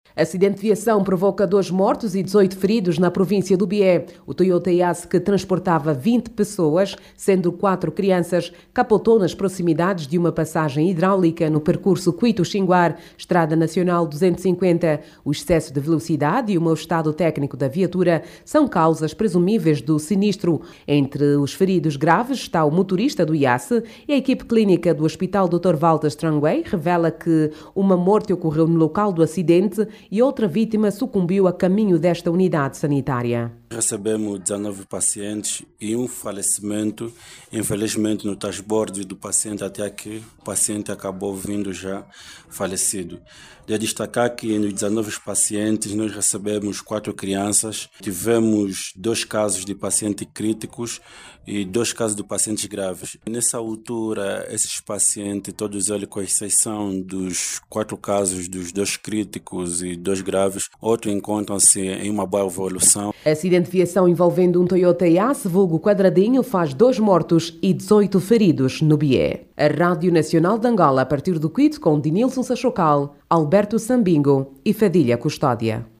Saiba mais dados no áudio abaixo com a repórter